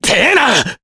Mitra-Vox_Damage_jp_03.wav